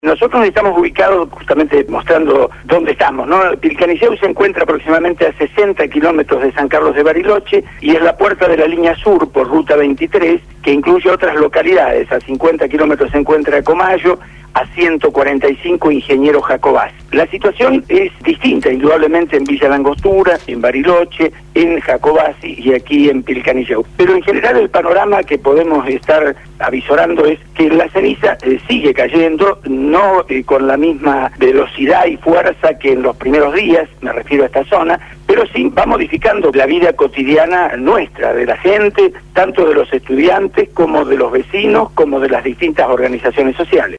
INFORME DESDE LA PATAGONIA: LA ERUPCIÓN DEL VOLCÁN PUYEHUE EN CHILE